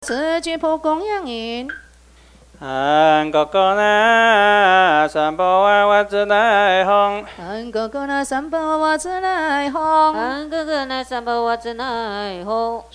佛教音樂  Mp3音樂免費下載 Mp3 Free Download